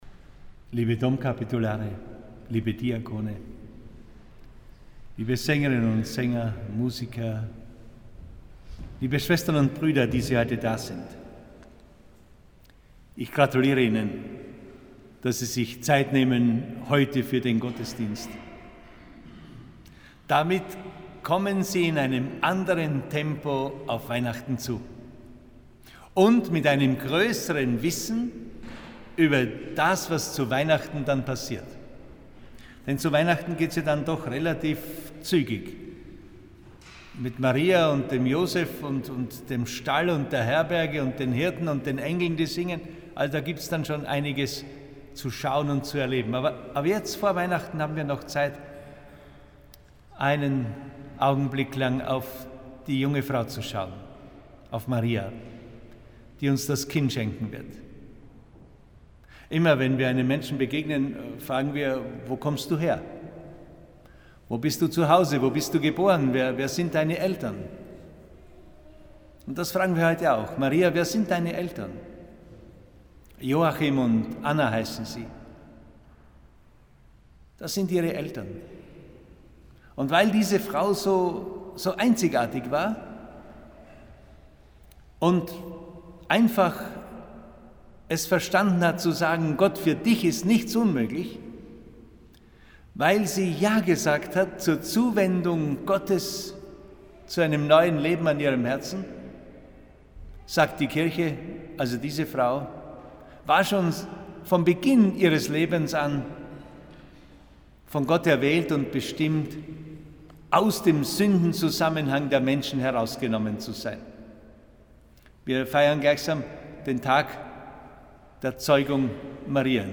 Predigt von Diözesanbischof Dr. Alois Schwarz am Hochfest Mariä Empfängnis im Dom zu Klagenfurt 10 MB 8.